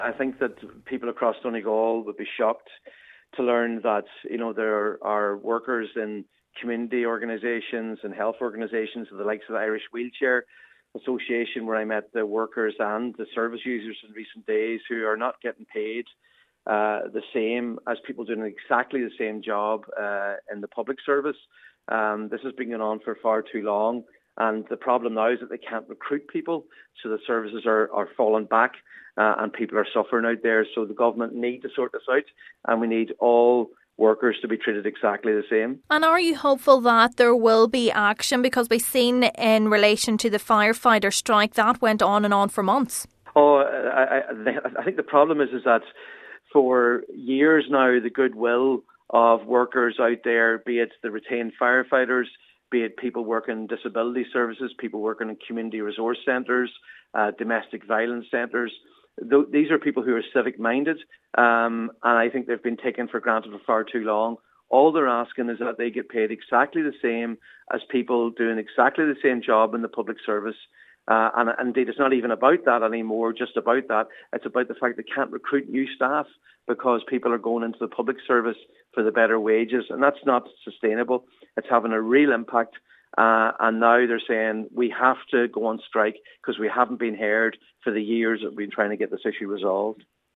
Donegal Deputy Padraig MacLochlainn says the strike notice must act as a wake up call for Government.